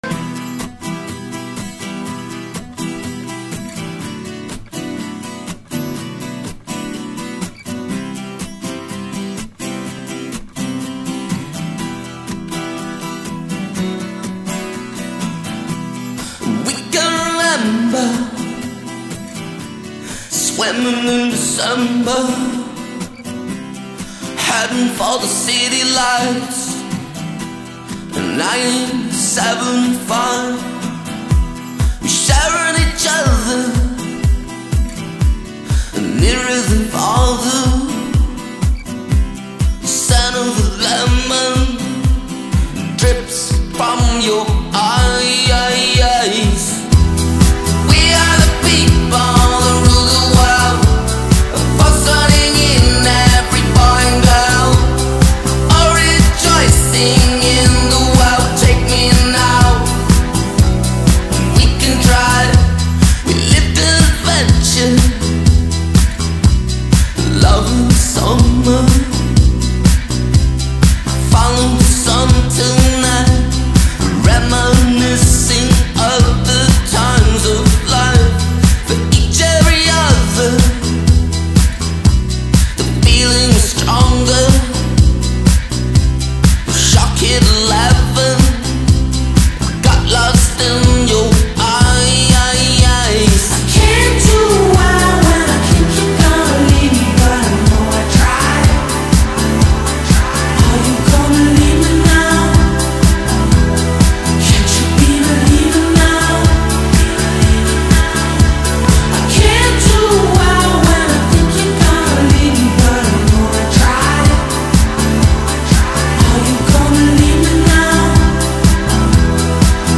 ترکیبی هست از پاپ، راک و موسیقی الکترونیک.